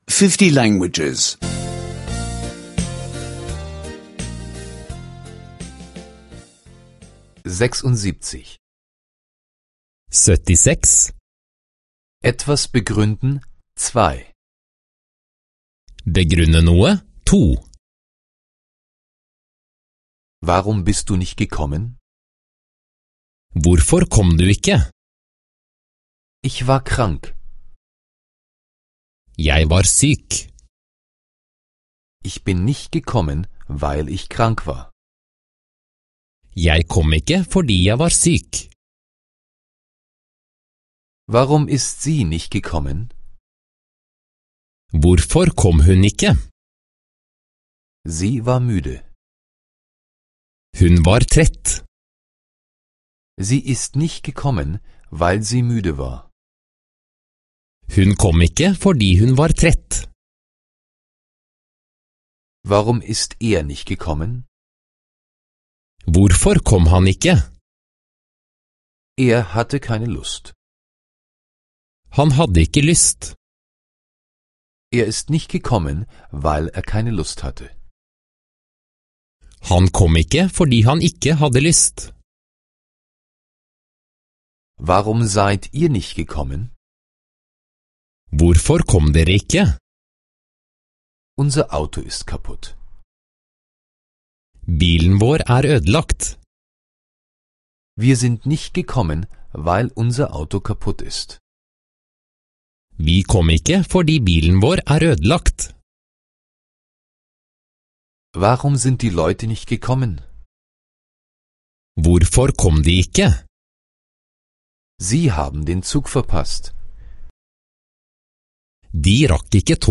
Norwegisch Sprache-Audiokurs (kostenloser Download)